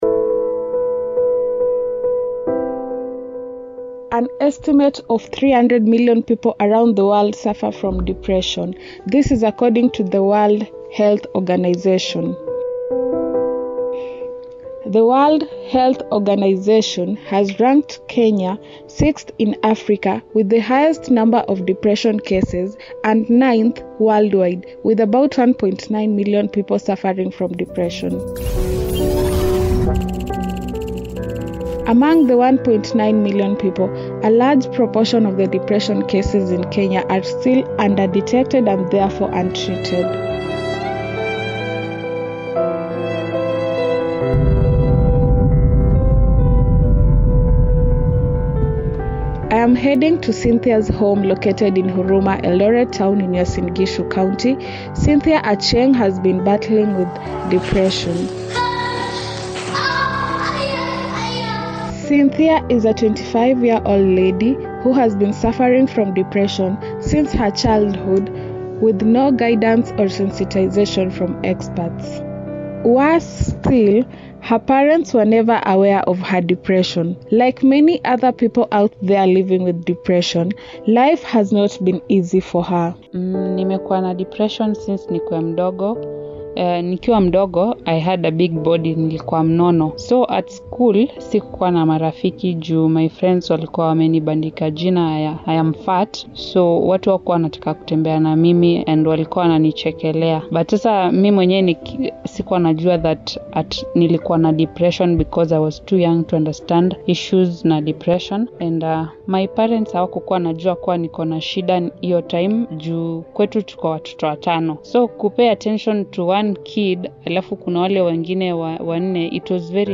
Radio Documentary: Dying Inside-1.9M Kenyans Suffering from Depression